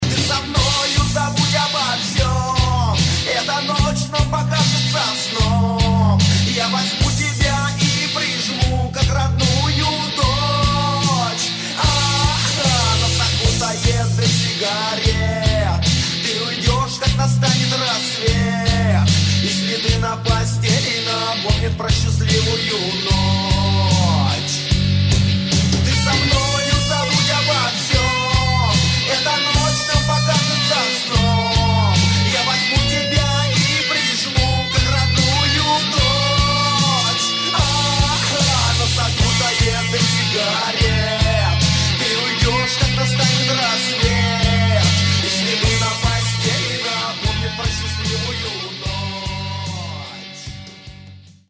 Рок рингтоны
Лирика